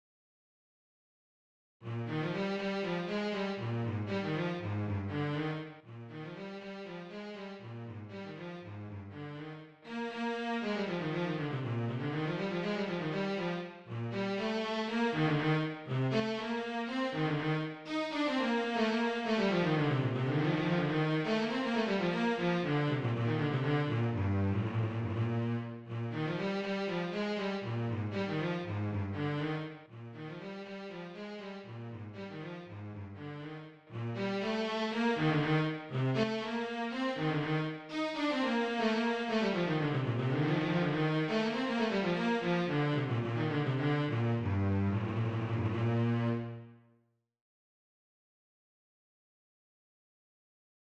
DIGITAL SHEET MUSIC - CELLO SOLO